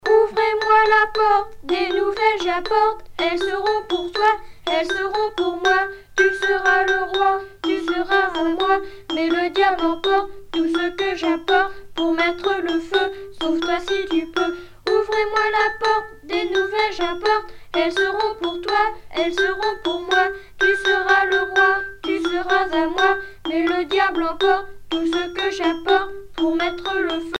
Genre brève
Catégorie Pièce musicale éditée